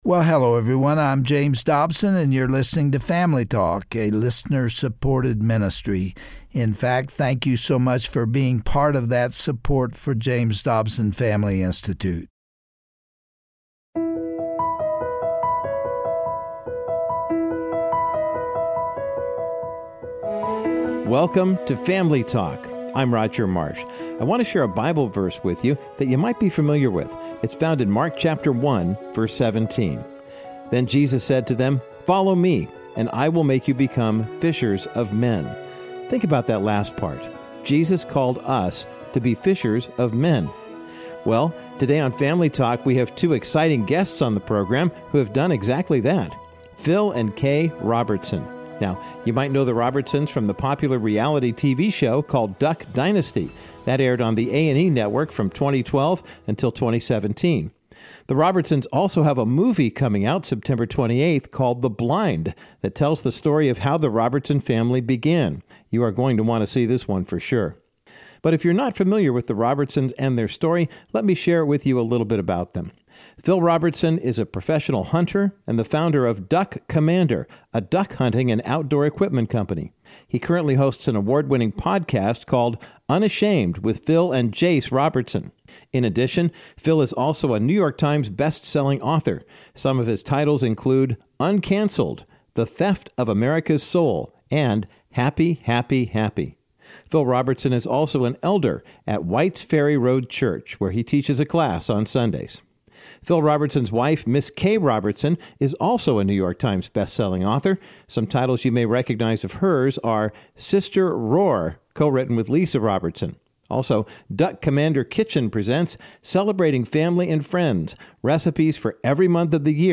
For Phil Robertson, salvation came in his mid-twenties, and it changed the trajectory of not only his life, but it also transformed his wife, Kay, and their family. On today's edition of Family Talk, Gary Bauer interviews Phil and Kay Robertson of "Duck Dynasty" fame, about their real life story of faith, hope and restoration, depicted in the soon to be released movie, "The Blind."